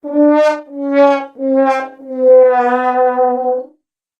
sad trombone sting]
sad-trombone.opus